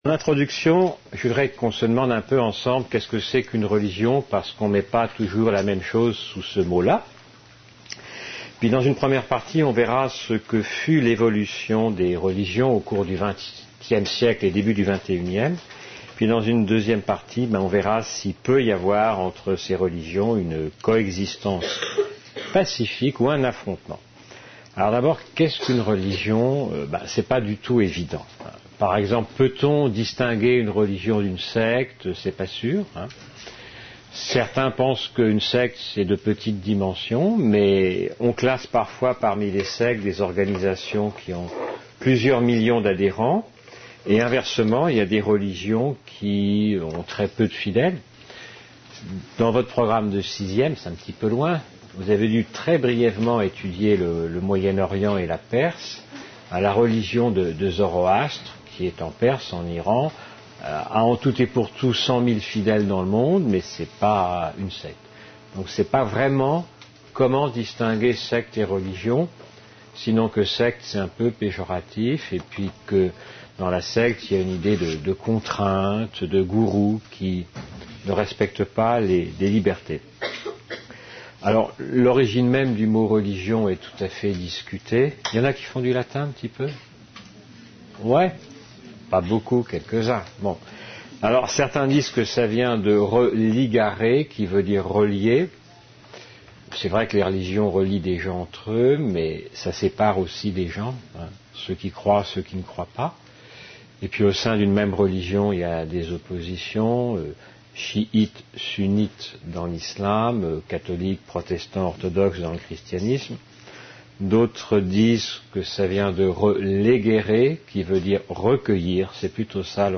Un conférence de L'UTLS au lycée avec Odon ValletLes religions Lycée André Malraux (77130 Montereau-Fault-Yonne)